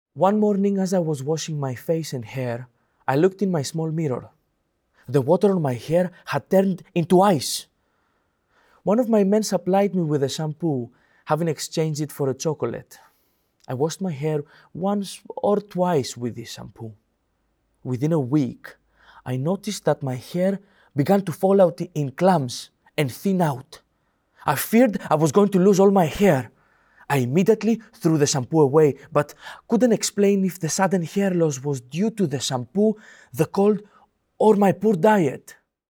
Audio dramatisation based on the Memoirs of a Prisoner.